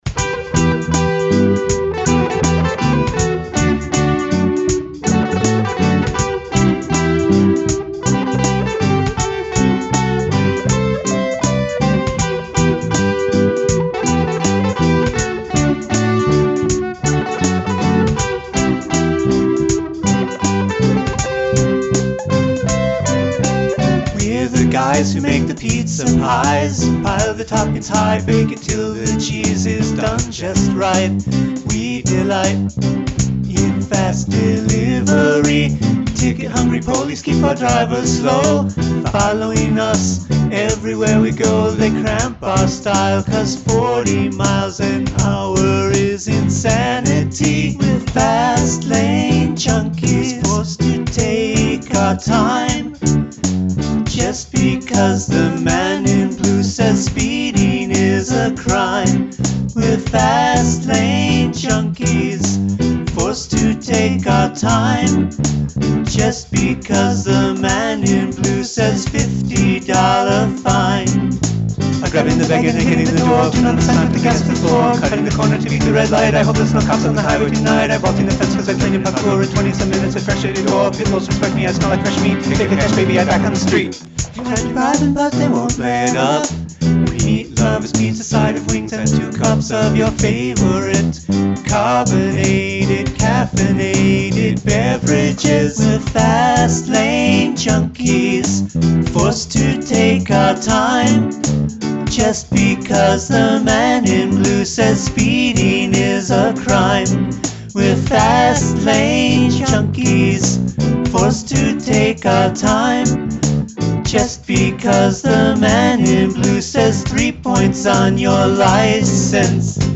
Must include a section with both vocals & music in double time
It's mainly a taste thing, whiteboy reggae with playschool melodies ain't my thing.